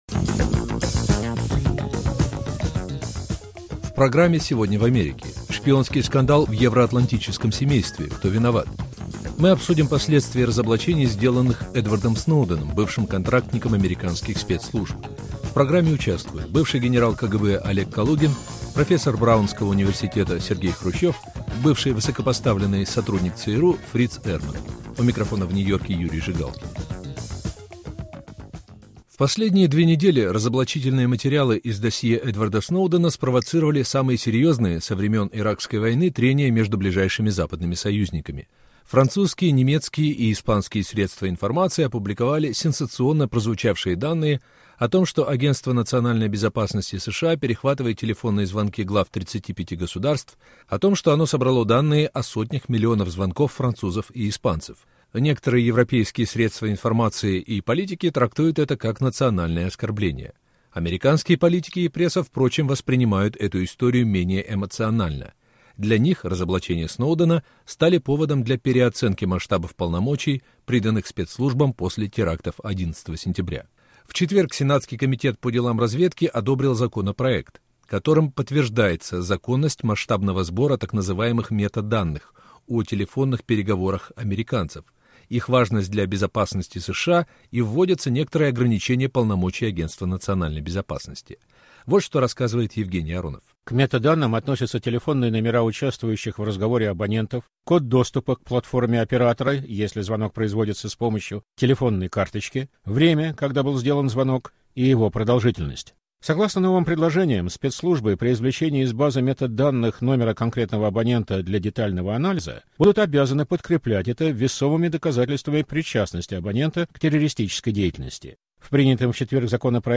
Прослушивание спецслужбами США европейских политиков и обычных граждан: "мыльный пузырь", раздутый СМИ, или угроза евроатлантическому партнерству? Обсуждают бывший генерал КГБ Олег Калугин и ученый, сын бывшего советского лидера Сергей Хрущев.